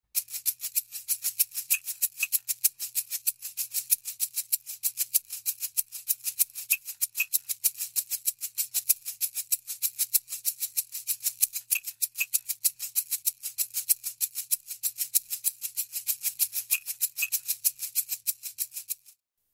Звуки для котов
Звуки для привлечения внимания кошек: Как заинтересовать вашего кота звуком погремушки